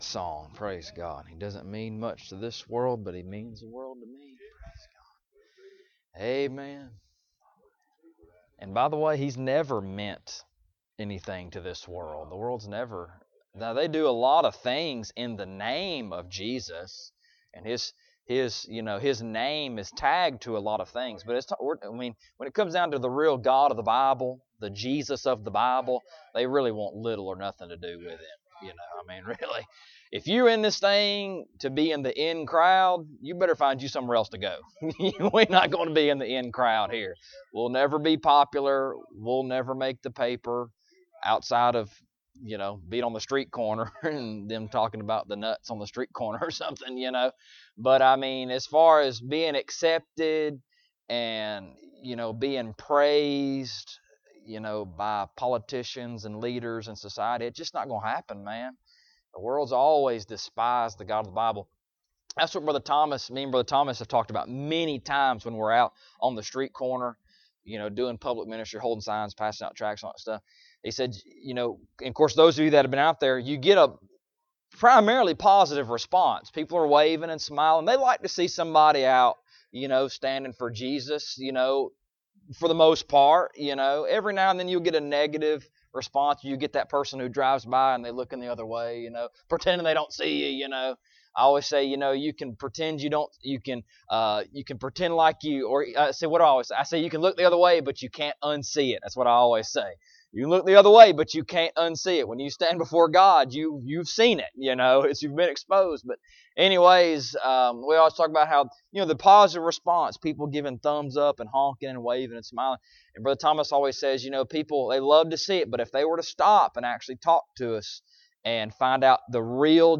Ephesians Passage: Ephesians 1; 3-14 Service Type: Sunday Morning Topics